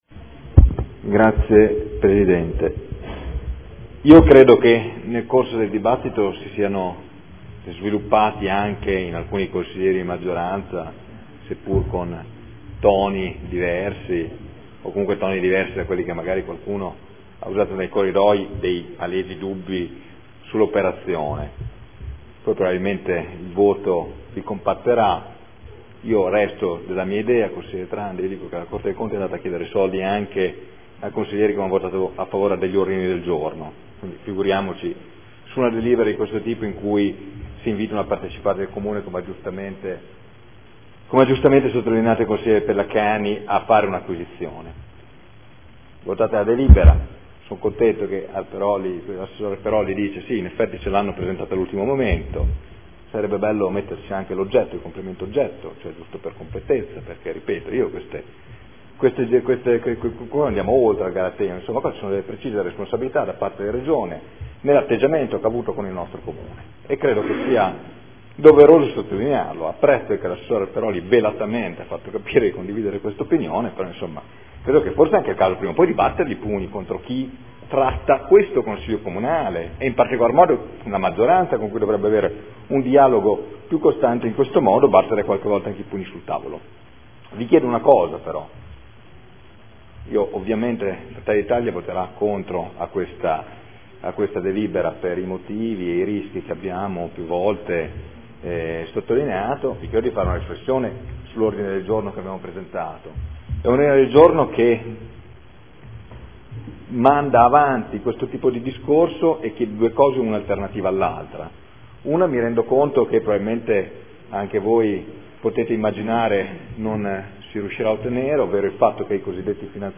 Michele Barcaiuolo — Sito Audio Consiglio Comunale
Seduta del 23/01/2014 Dichiarazione di Voto. Adesione del Comune di Bologna a Emilia Romagna Teatro Fondazione in qualità di socio fondatore necessario.